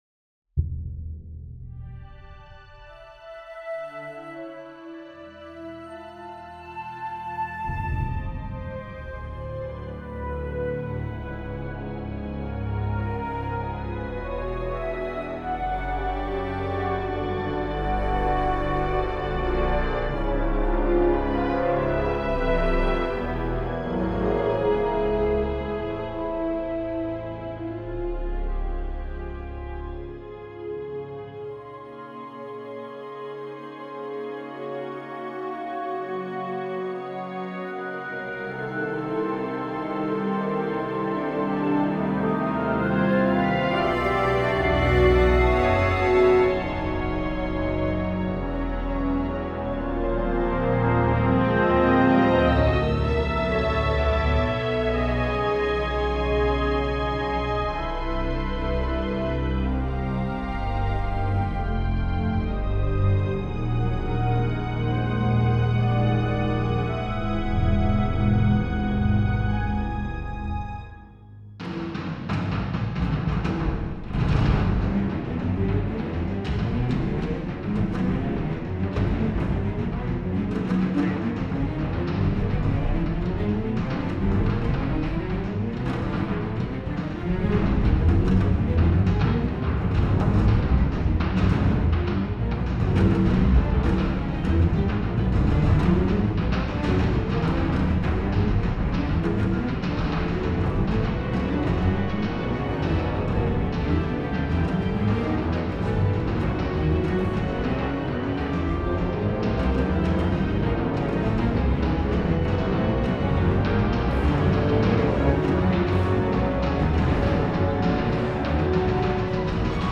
two orchestras … three choirs